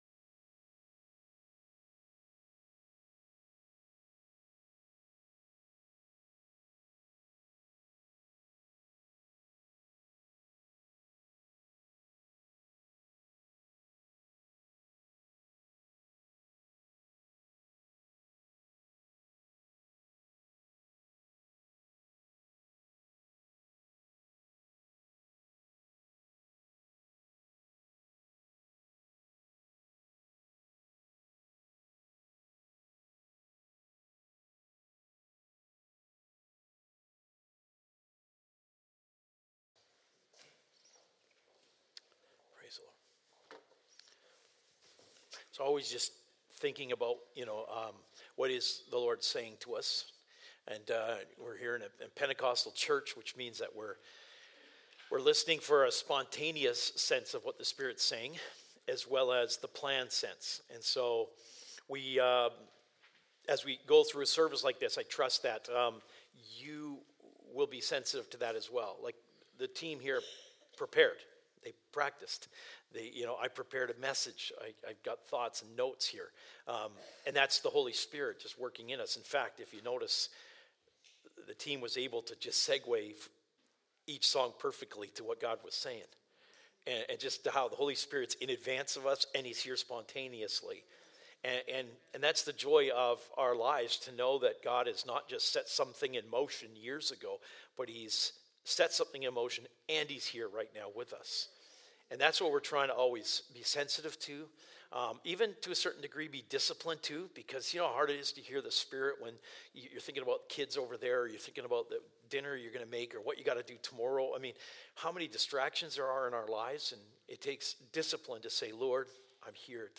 Sermons | Lighthouse Church